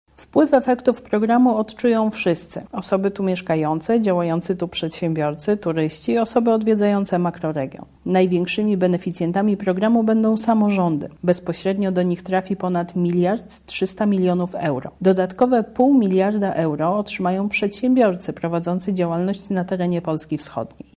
O tym kto odczuje wpływ programu mówi wiceminister Funduszy i Polityki Regionalnej Małgorzata Jarosińska-Jedynak: